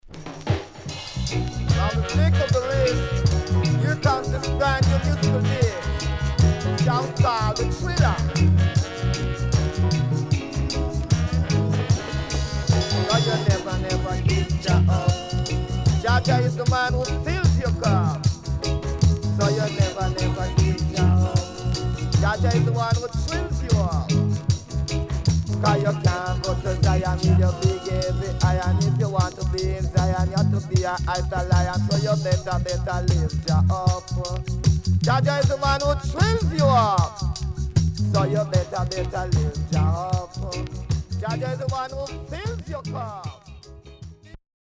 SIDE A:所々チリノイズ入ります。